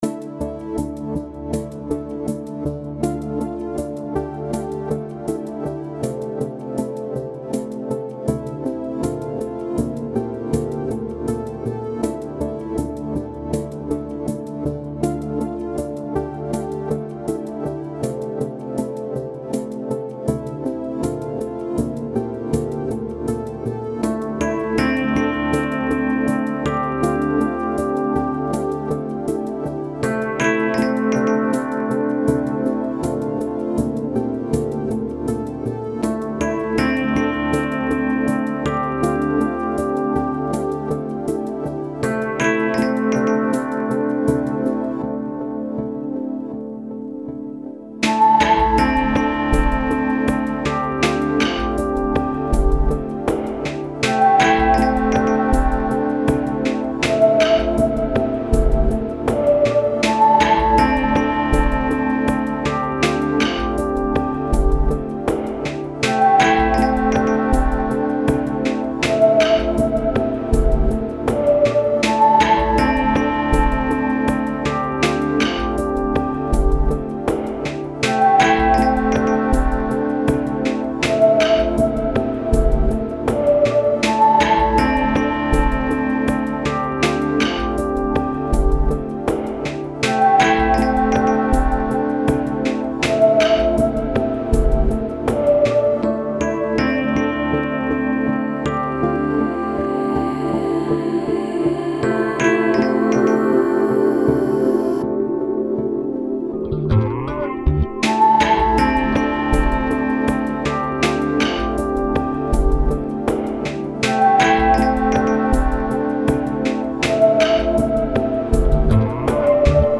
Genre Downbeat